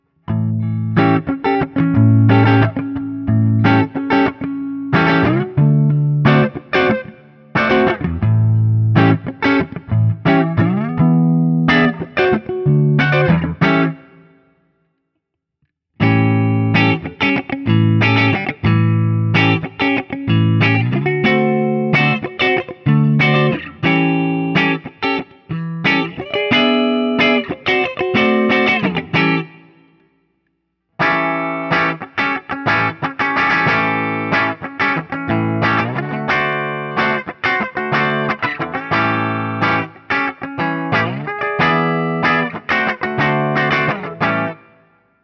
It’s got a different character than the same model with the LB1 pickups, and the tone can get that great raw P90 snarl that I love so much.
ODS100 Clean
7th Chords
I played through the QSC K12 speaker recorded direct into my Macbook Pro using Audacity.
For each recording I cycle through the neck pickup, both pickups, and finally the bridge pickup. All knobs on the guitar are on 10 at all times.